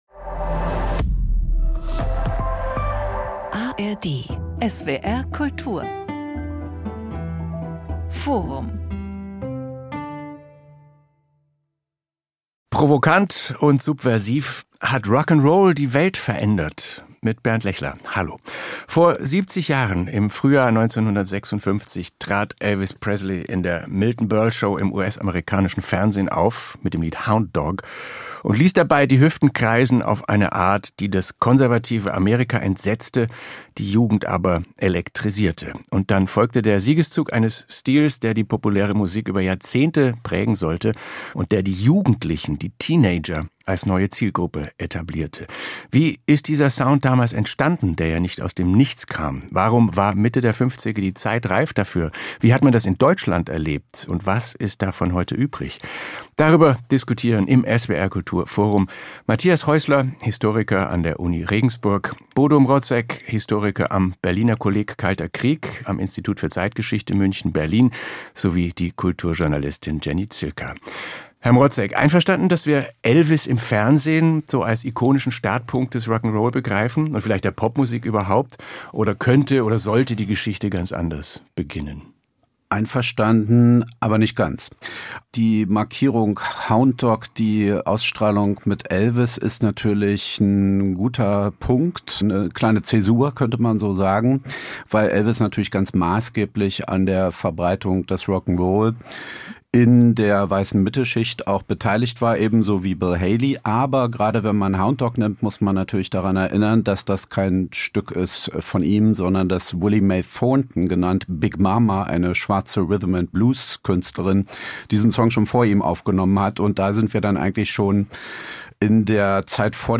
Historiker